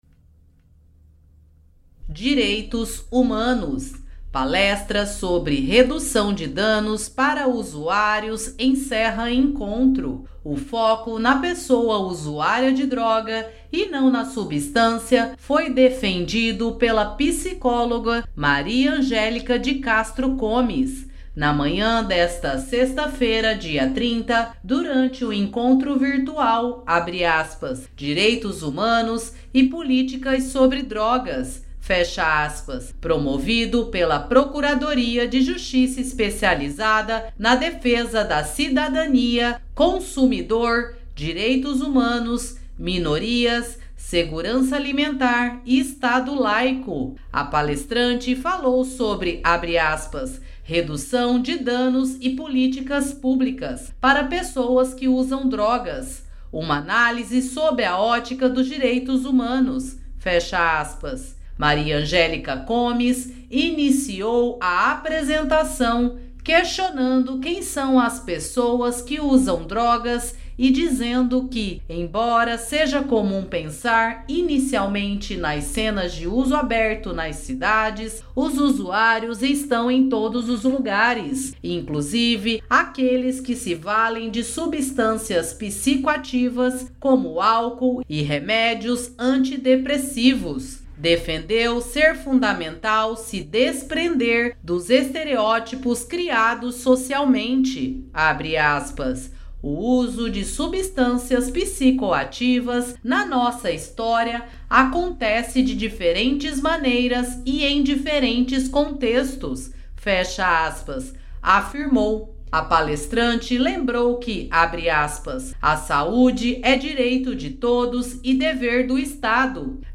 Palestra sobre redução de danos para usuários encerra encontro
Palestra sobre redução de danos para usuários encerra encontro.mp3